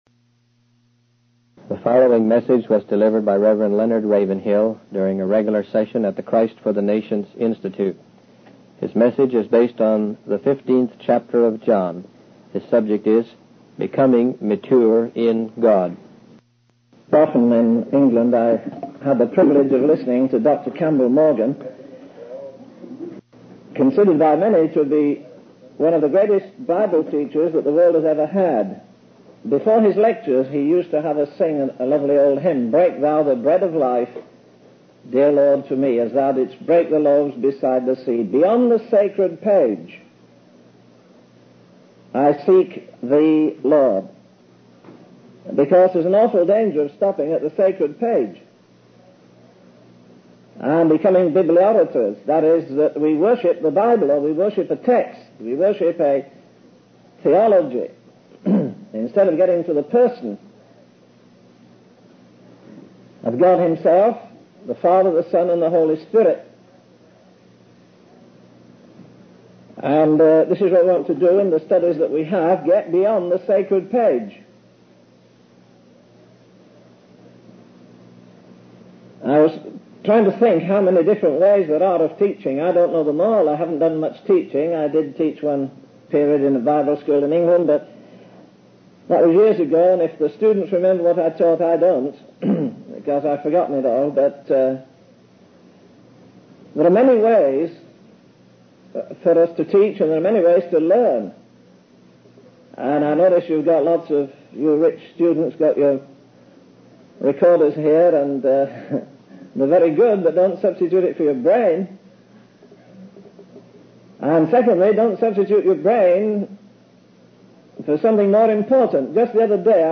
In this sermon, Reverend Leonard Ravenhill discusses the importance of going beyond simply reading the Bible and becoming 'bibliographers.' He emphasizes the need to seek the Lord beyond the sacred page and to be filled with His grace.